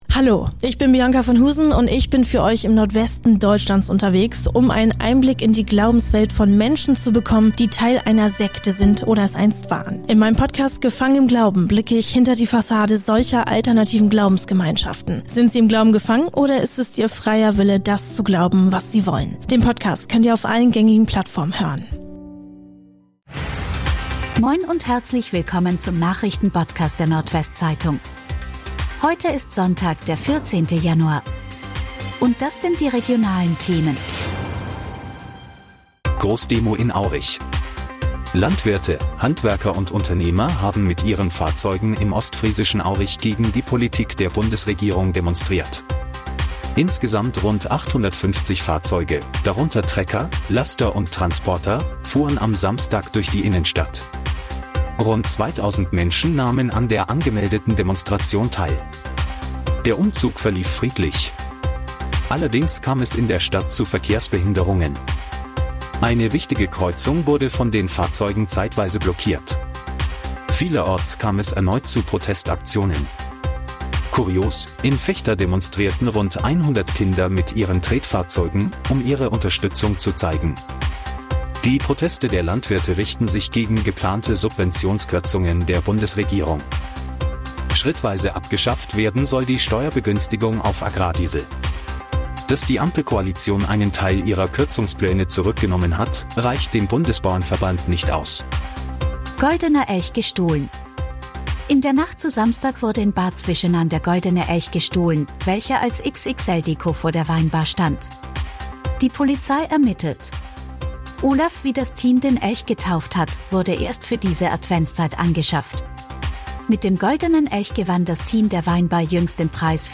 NWZ Nachrichten Botcast – der tägliche News-Podcast aus dem Norden
Nachrichten